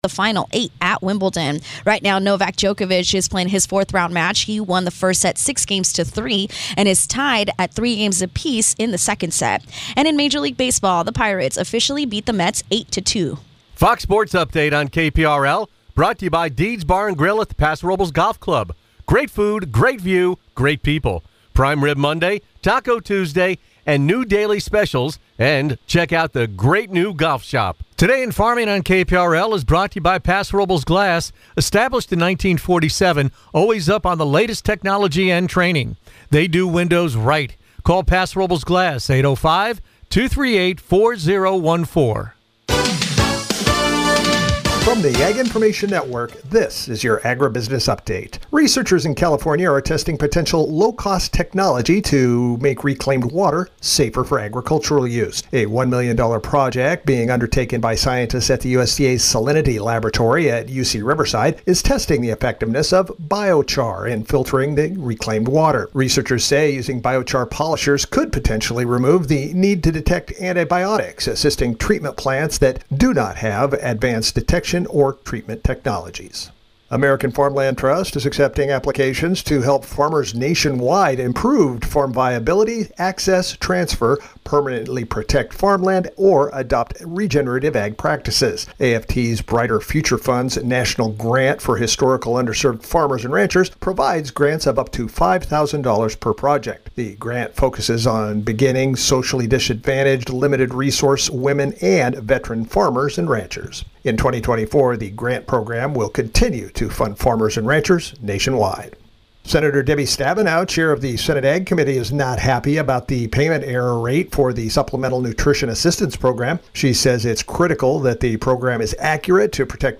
Tune in to the longest running talk show on the Central Coast.